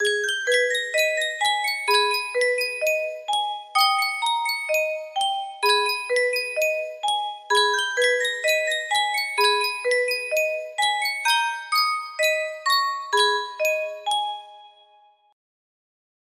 Yunsheng Music Box - Joshua Fit the Battle of Jericho 1588
Full range 60